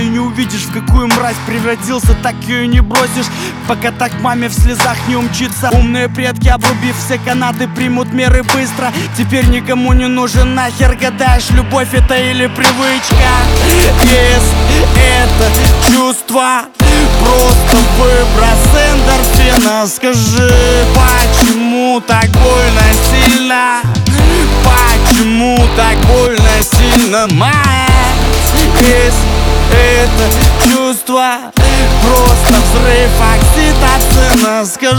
Pop Hip-Hop Rap